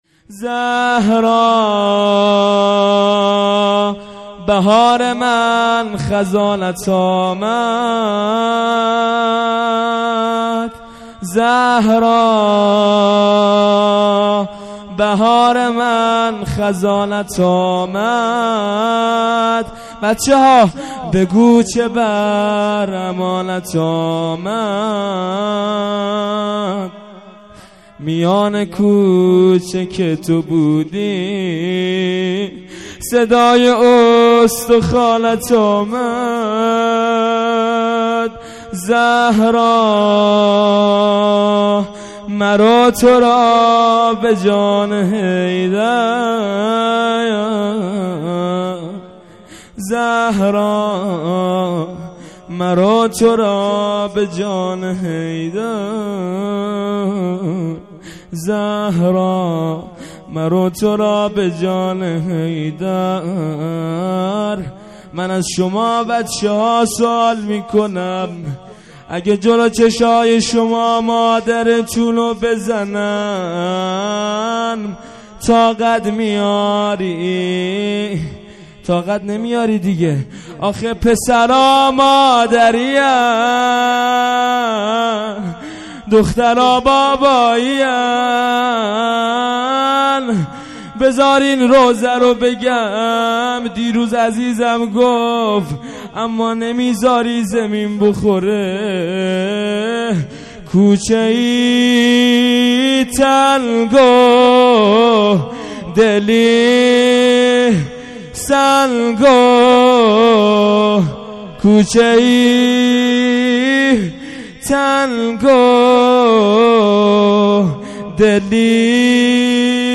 هیئت مکتب الرضا علیه السلام دلیجان
روضه | شهادت حضرت زهرا